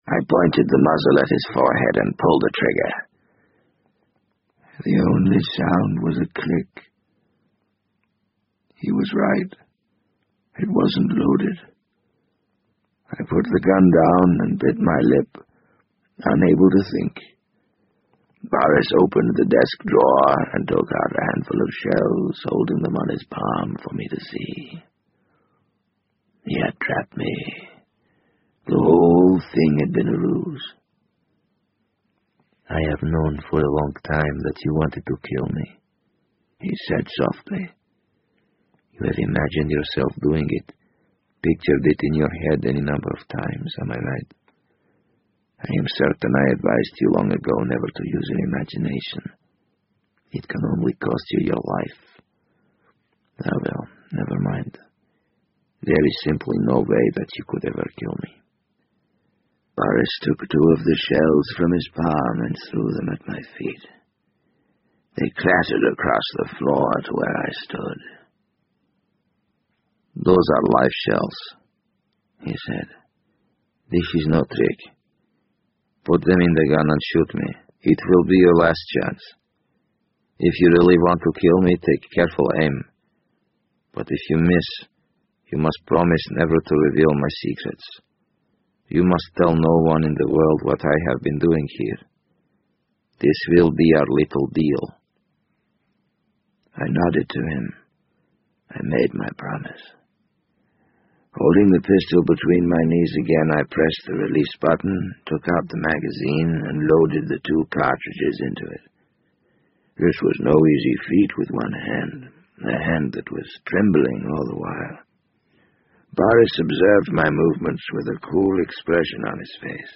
BBC英文广播剧在线听 The Wind Up Bird 014 - 18 听力文件下载—在线英语听力室